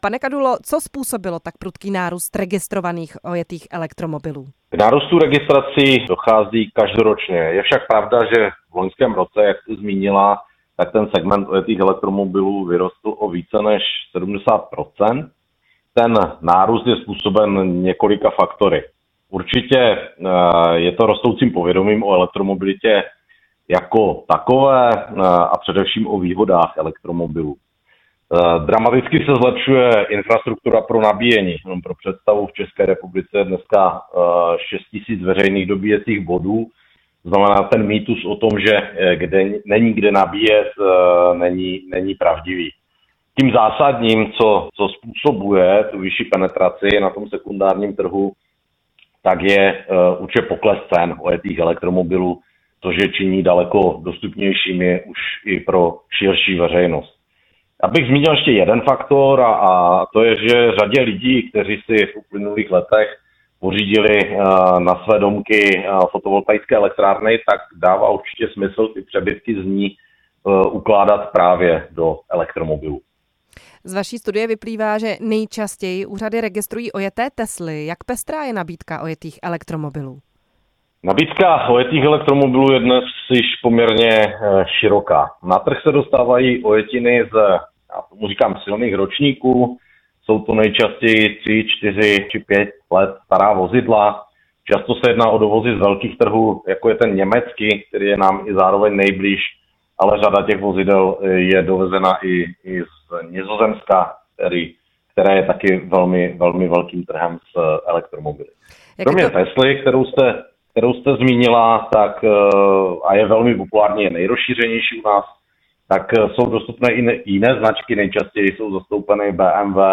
Elektromobily teď v Česku dobývají také trh s ojetými vozy. Vloni jejich registrace narostly o 74 % oproti předchozímu roku a dosáhly počtu čtyř tisíc aut. Na podrobnosti se moderátorka